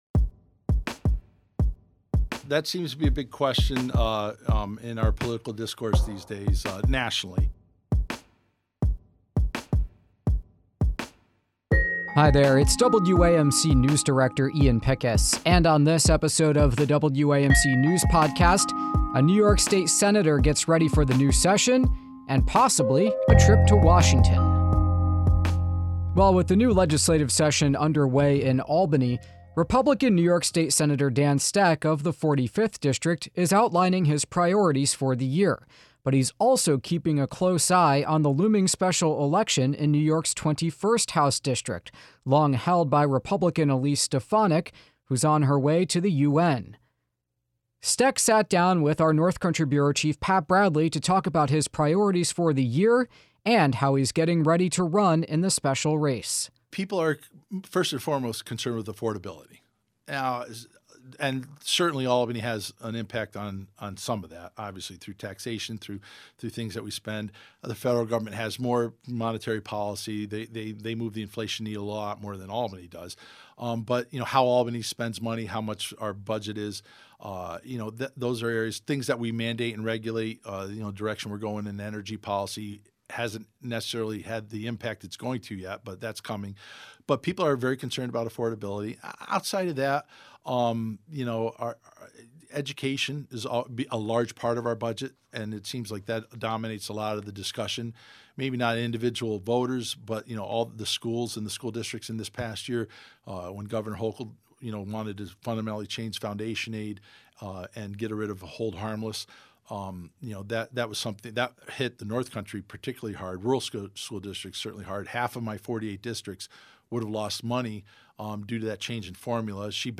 We speak with New York state Senator Dan Stec, a Republican from the 45th district who wants to go to Congress to represent the 21st House district.